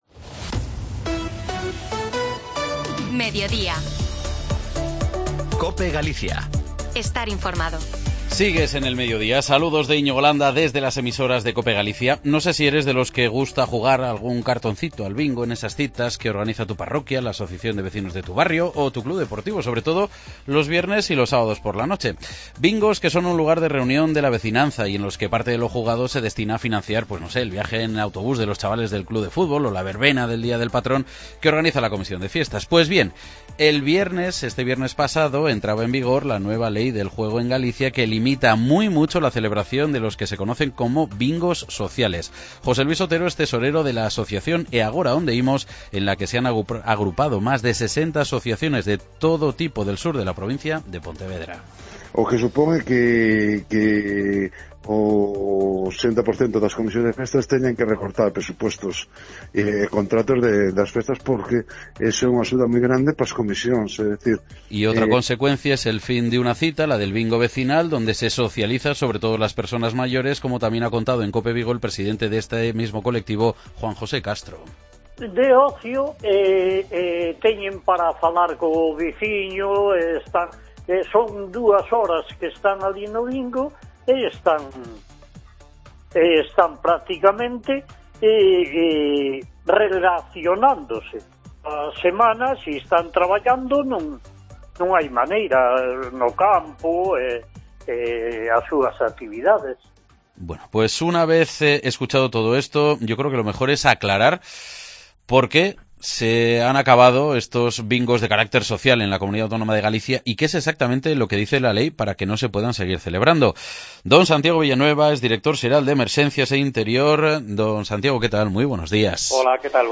Análisis en el Mediodía COPE de la situación de los "bingos sociales" en Galicia con la nueva ley